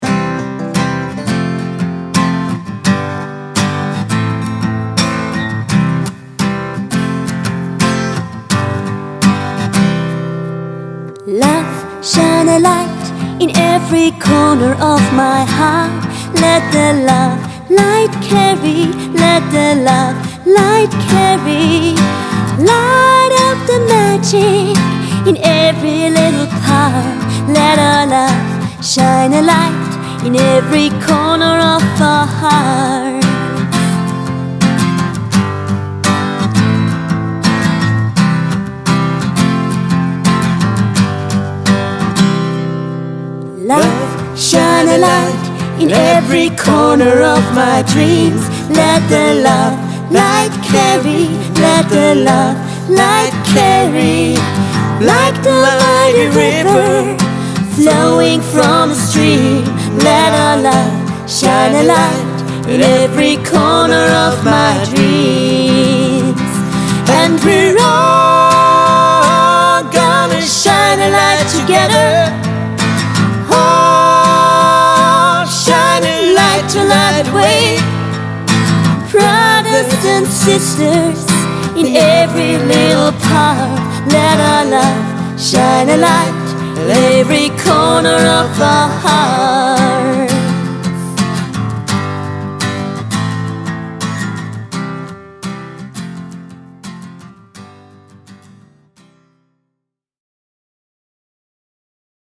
Solo oder Duo
Hochzeitssängerin